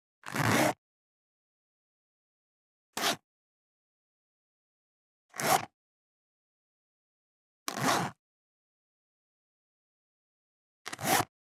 26.ジッパーを開ける【無料効果音】
ASMRジッパー効果音
ASMR